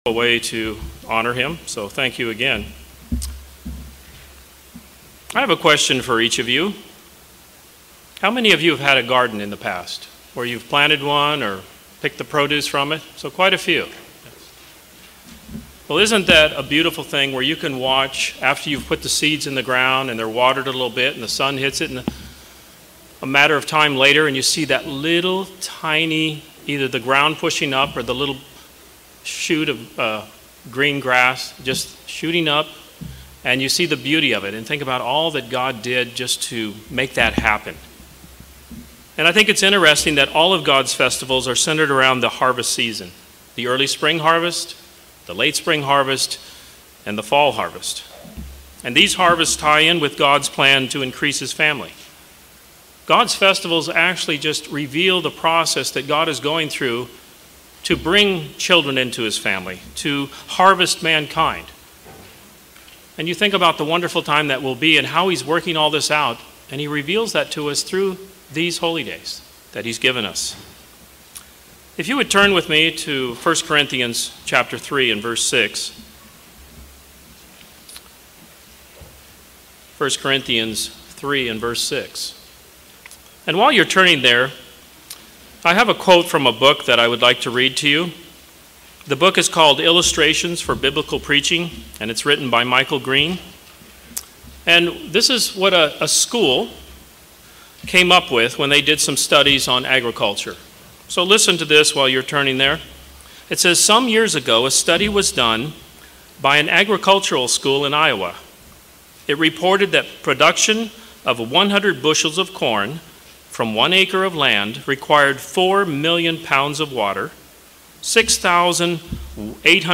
This sermon discusses four points, along with the wave sheaf offering.